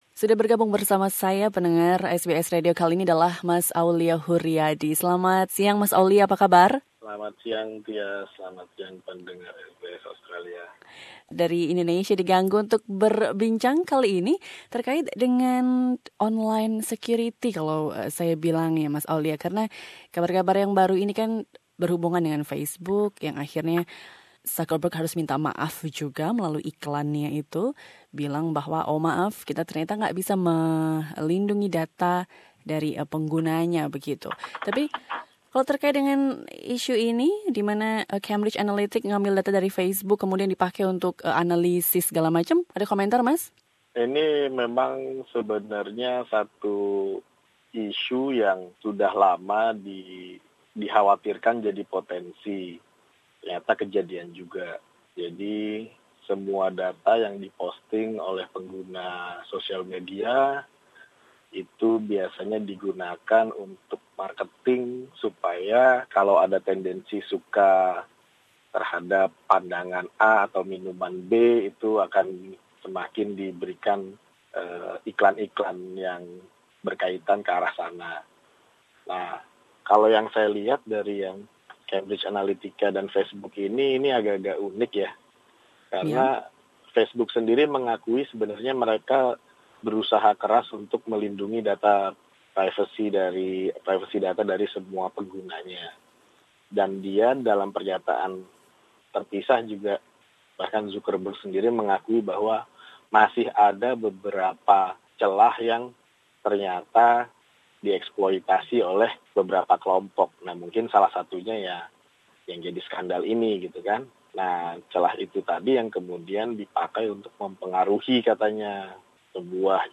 Facebook dikaitkan dengan skandal pengambilan data yang diduga dilakukan oleh Cambridge Analytica. Data jutaan penggunanya bocor, membuat CEO Facebook Mark Zuckerberg menyampaikan permintaan maaf melalui beberapa surat kabar di Inggris dan Amerika Serikat. SBS Radio mewawancarai